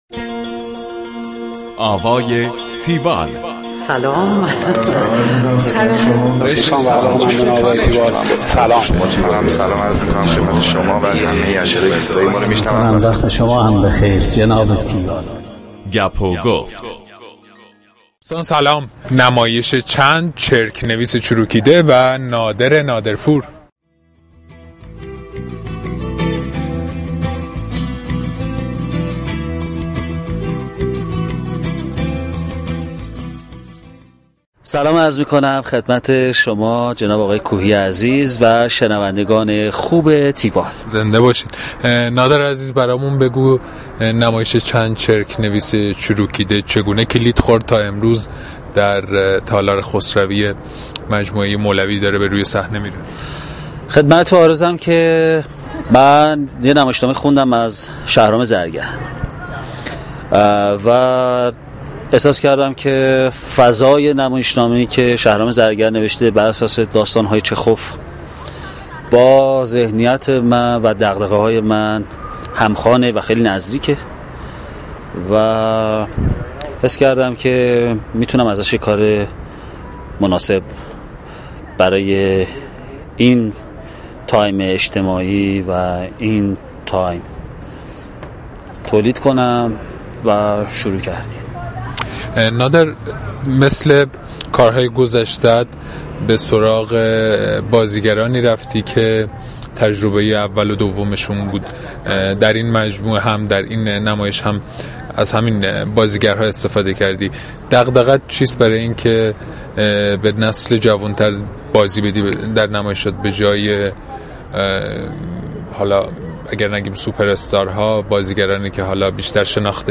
tiwall-interview-nadernaderpoor.mp3